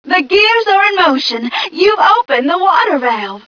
1 channel
mission_voice_m3ca024.wav